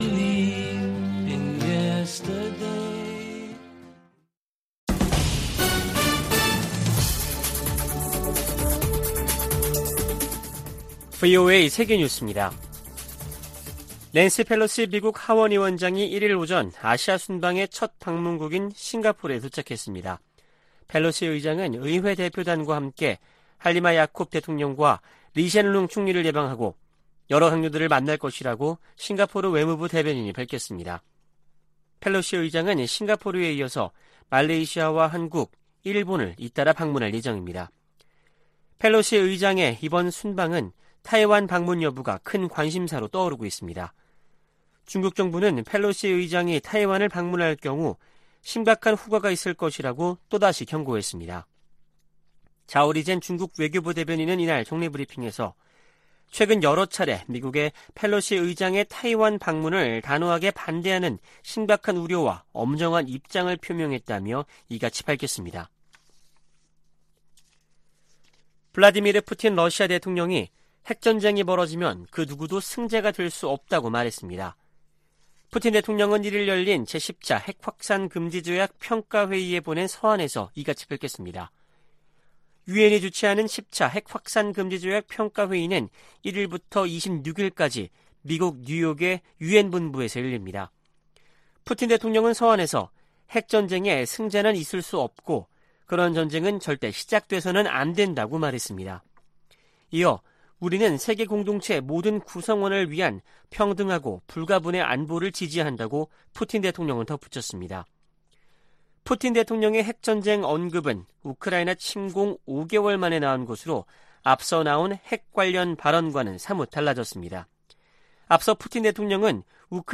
VOA 한국어 아침 뉴스 프로그램 '워싱턴 뉴스 광장' 2022년 8월 2일 방송입니다. 백악관 국가안보회의(NSC) 고위관리가 미-한 연합훈련과 관련해 준비태세의 중요성을 강조하고, 한반도 상황에 맞게 훈련을 조정하고 있다고 밝혔습니다. 미 국방부는 중국의 사드 3불 유지 요구와 관련해 한국에 대한 사드 배치는 두 나라의 합의에 따라 결정될 것이라는 입장을 밝혔습니다. 밥 메넨데즈 미 상원 외교위원장이 '쿼드'에 한국을 포함해야 한다고 말했습니다.